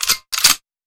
Gun Load.wav